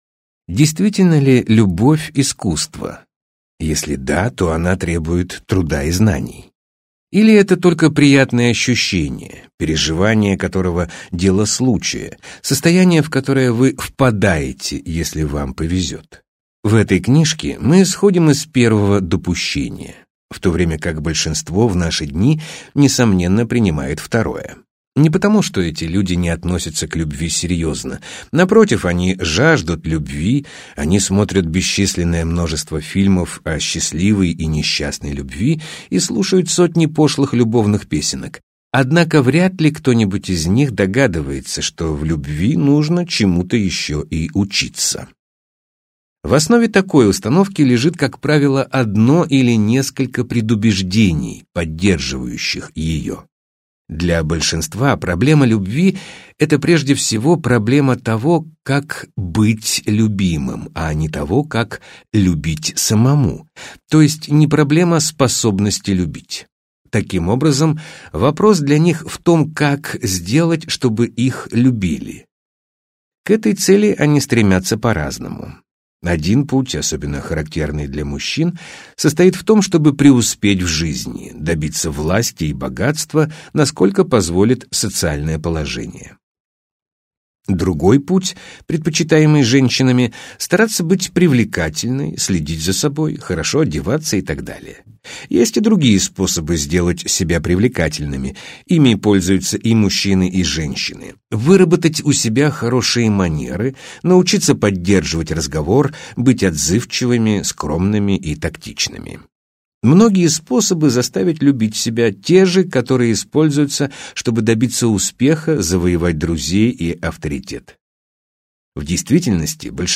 Аудиокнига Искусство любить | Библиотека аудиокниг
Прослушать и бесплатно скачать фрагмент аудиокниги